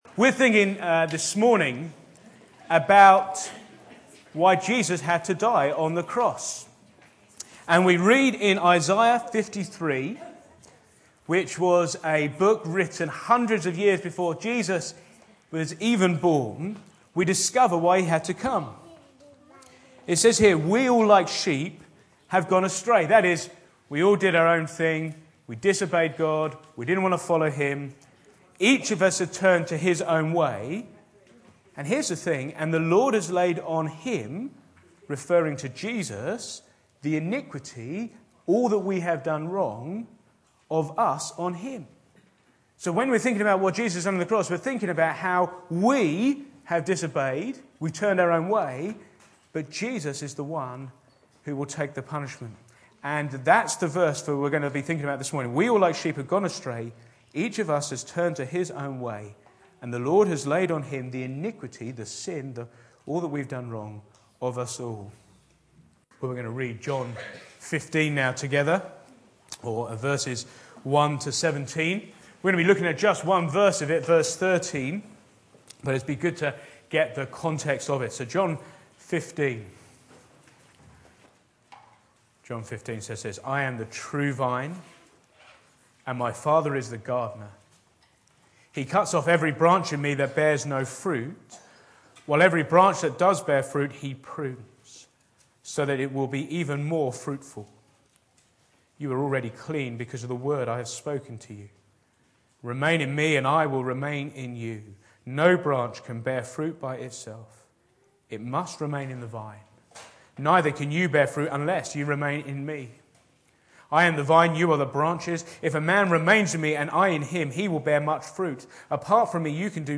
Back to Sermons Christ took my place